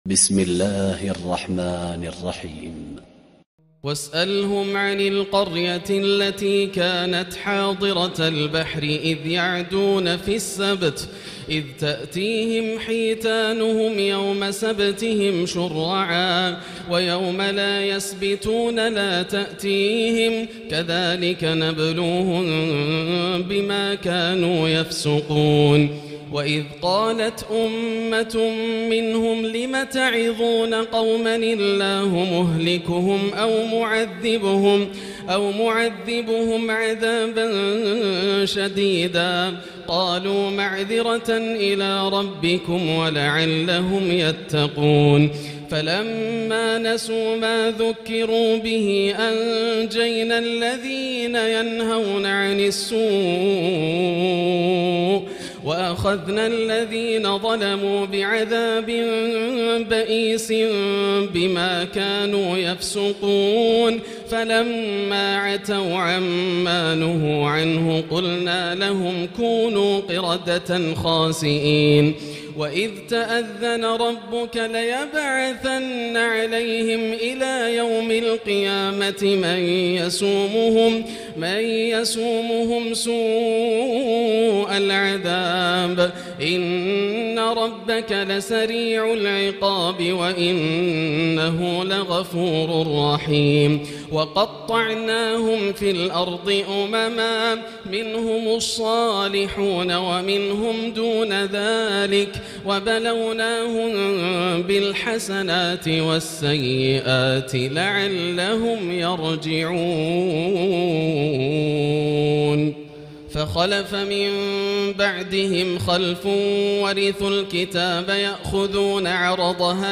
الليلة الثامنة تتمة سورة الأعراف163-206 وفواتح الأنفال1-40 > الليالي الكاملة > رمضان 1439هـ > التراويح - تلاوات ياسر الدوسري